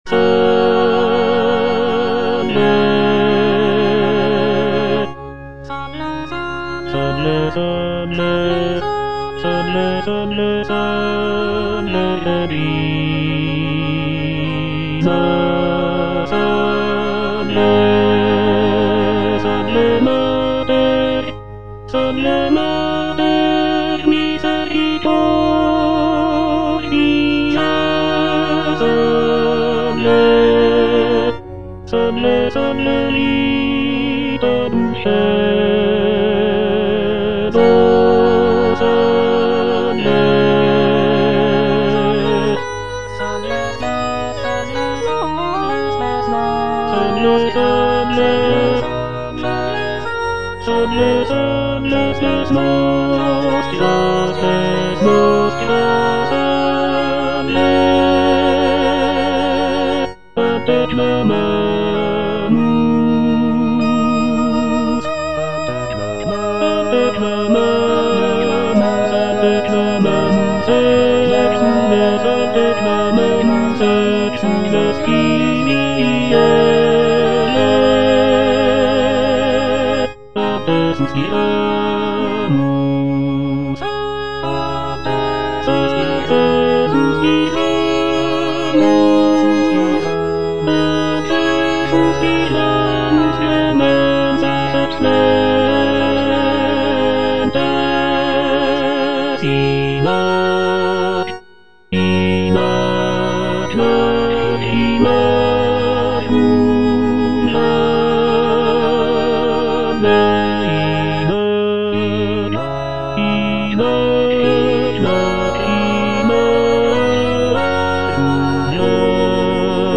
sacred choral work